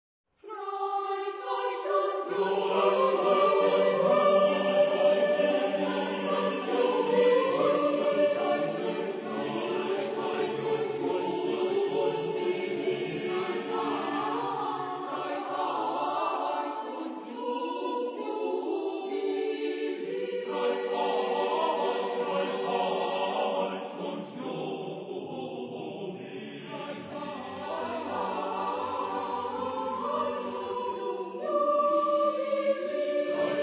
Epoque: 16 ; 17th century
Genre-Style-Form: Sacred
Type of Choir: SSATTB  (6 mixed voices )